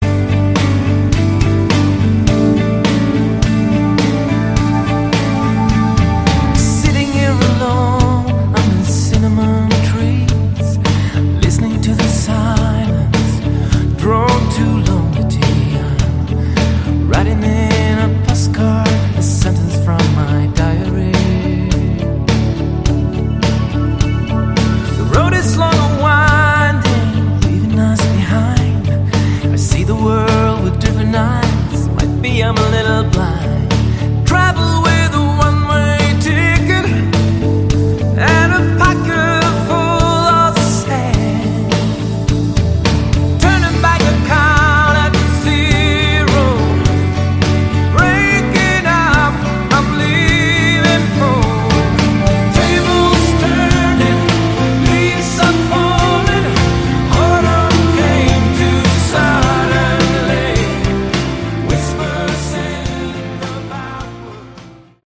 Category: AOR
Rough Mix